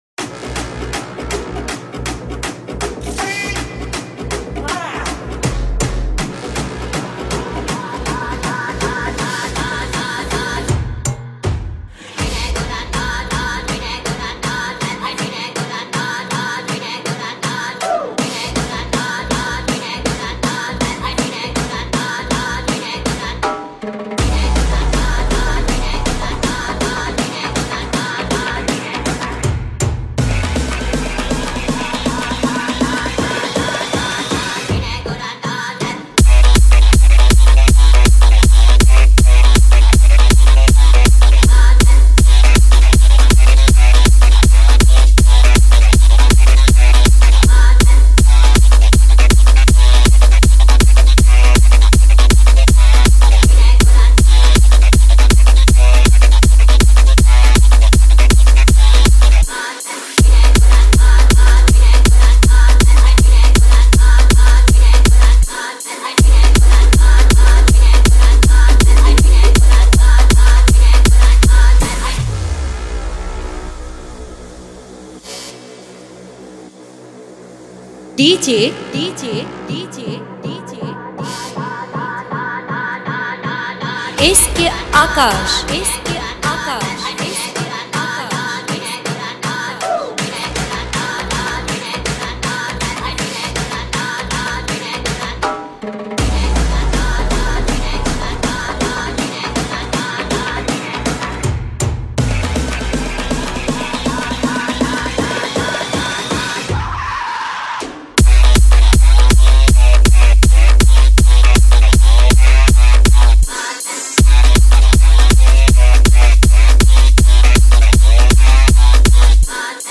Bangla Dj Gan